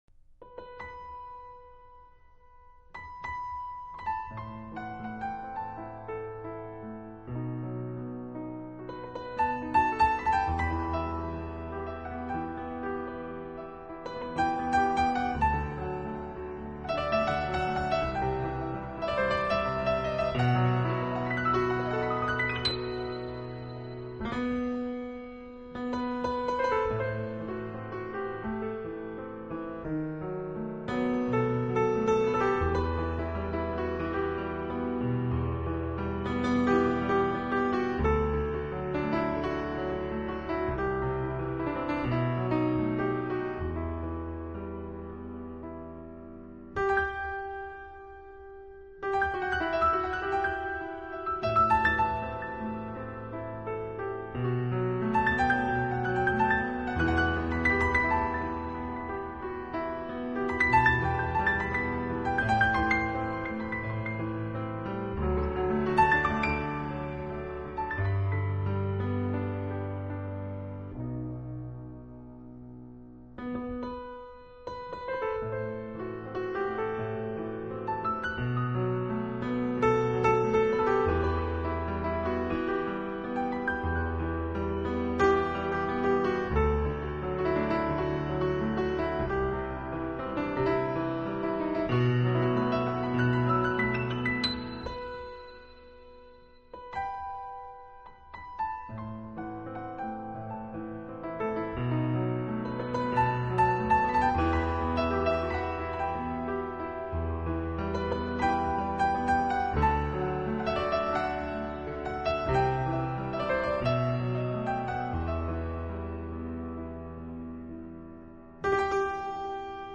音乐风格：Easy Listening